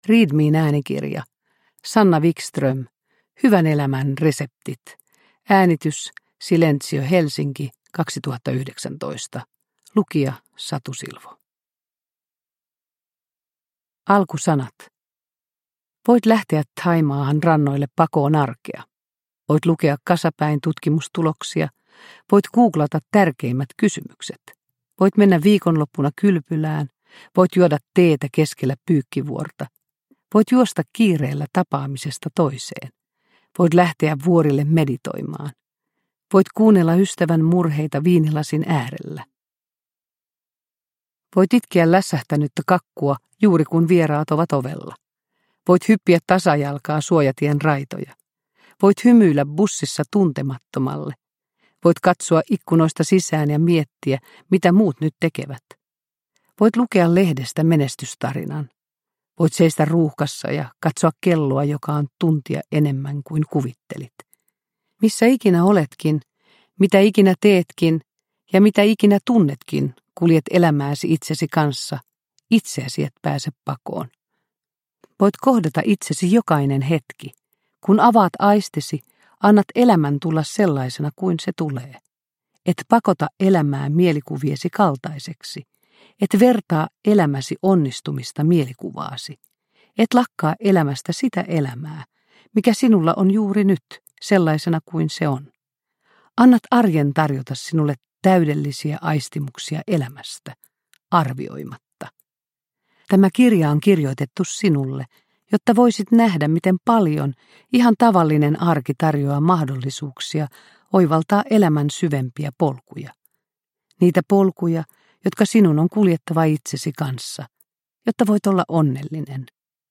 Hyvän elämän reseptit – Ljudbok
Uppläsare: Satu Silvo